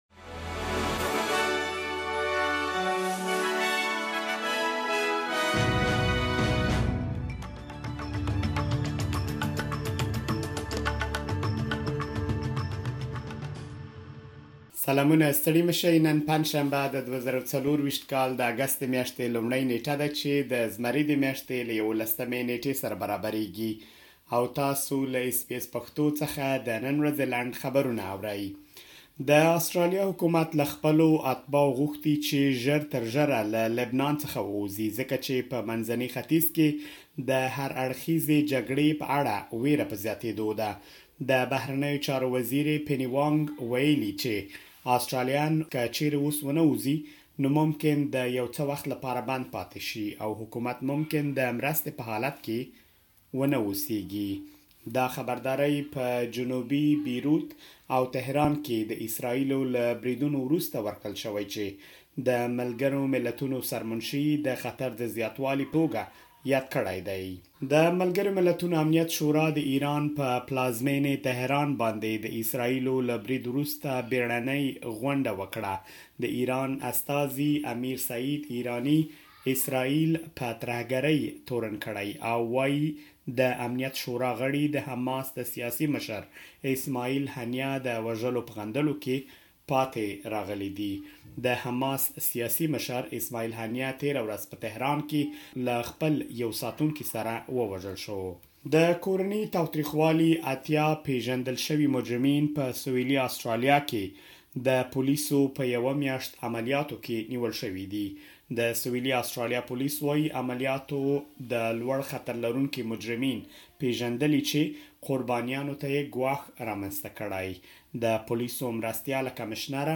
د اس بي اس پښتو د نن ورځې لنډ خبرونه|۱ اګسټ ۲۰۲۴
د اس بي اس پښتو د نن ورځې لنډ خبرونه دلته واورئ.